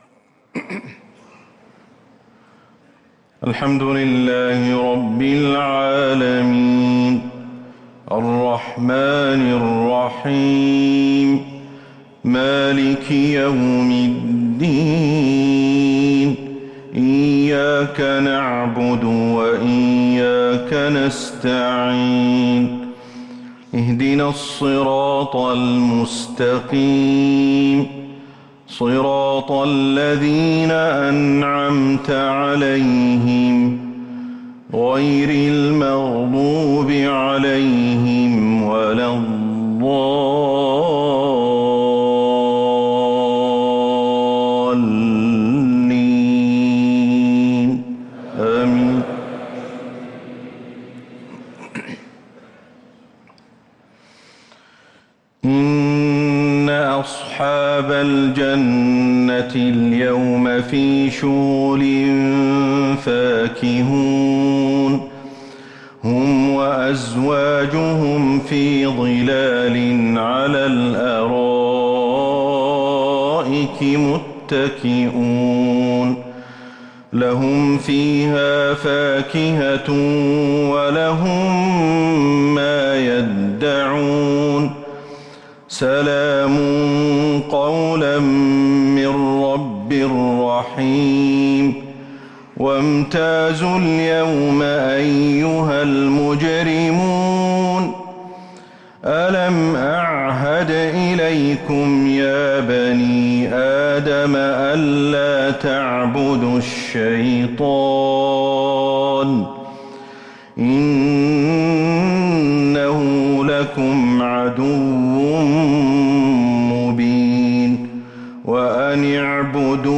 عشاء الثلاثاء 1 شعبان 1444هـ من سورة يس > 1444هـ > الفروض - تلاوات الشيخ أحمد الحذيفي